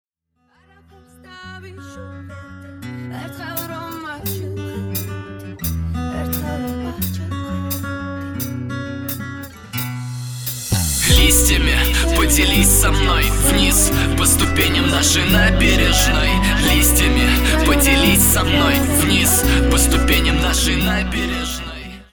• Качество: 320, Stereo
красивые
лирика